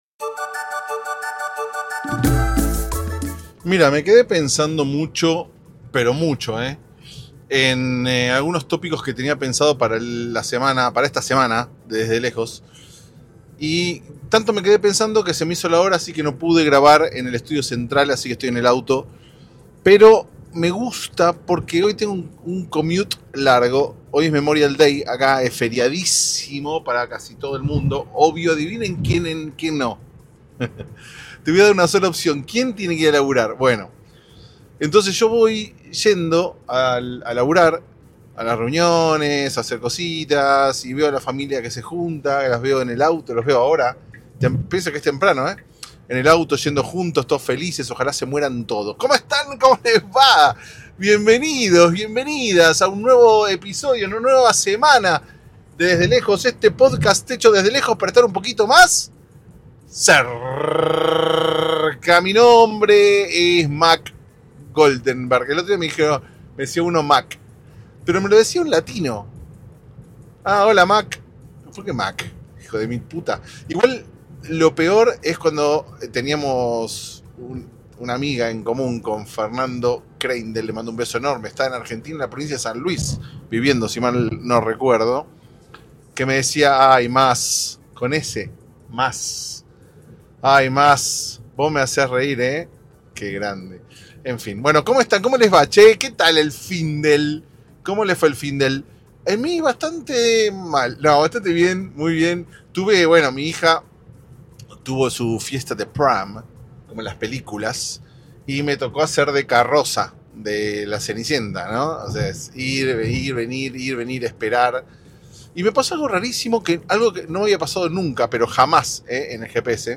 Ahi vamos, en la ruta californiana, tirando magia podcasteril. Eso me imagino yo pero luego aparece el meme de expectativa vs. realidad y todo se va al tacho.